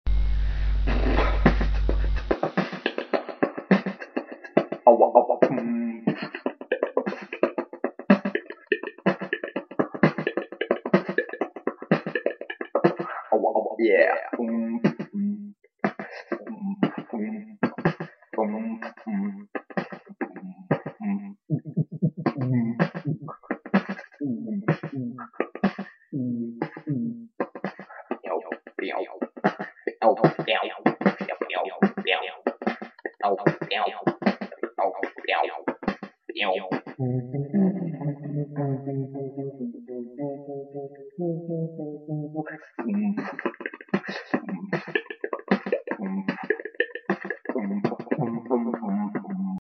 а через что ты записывал? эхо мешает wink ну а так по делу то как и почти у всех новечков нужно работать над ритмом, и еще вокал скретч подработать
Первый недочёт, это воспроизведение звука с микрофона на колонки, тем более во время записи, а второй недочёт в том, что ты не туда обратился со своими недочётами.